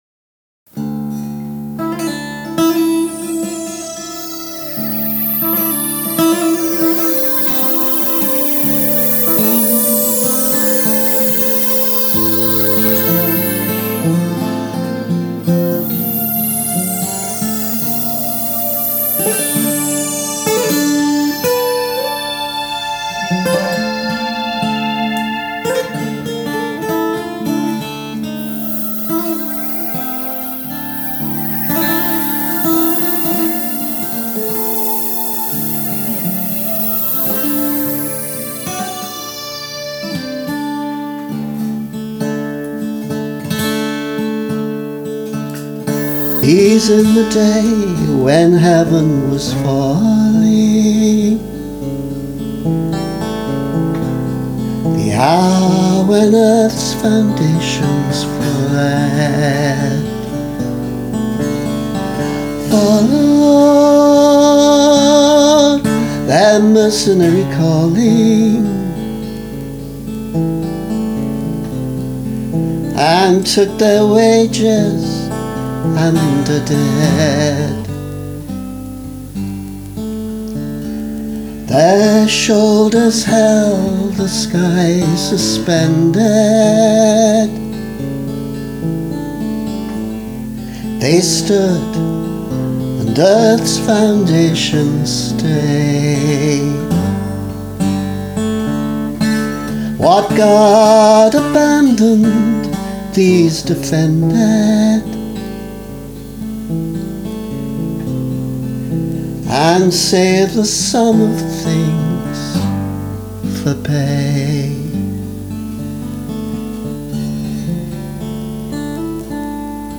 I didn’t like the vocal much, but I did like the synth and guitars, so I’ve done a little splicing and remixing, though the vocal needs redoing.